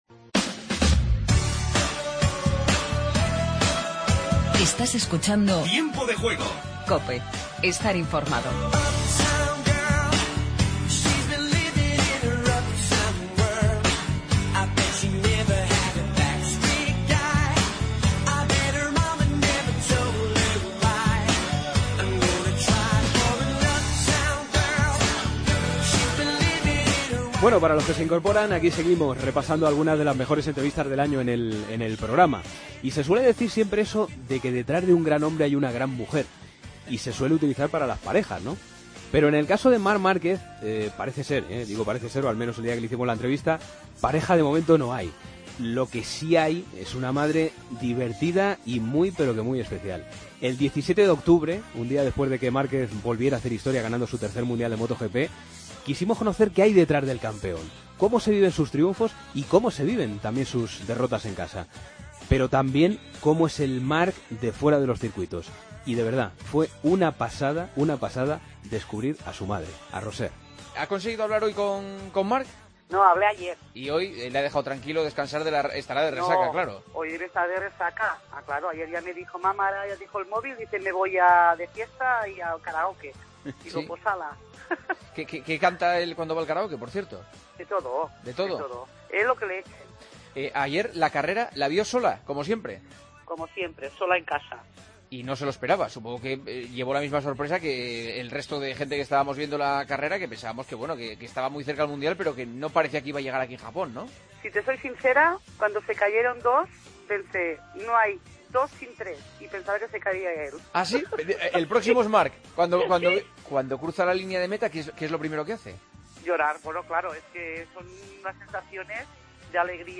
El Sevilla consiguió su tercera Europa League, el día después hicimos el programa especial desde Sevilla.
Novak Djokovic nos atendió en pleno Madrid Mutua Open. Tiempo de Juego Con Paco González, Manolo Lama y Juanma Castaño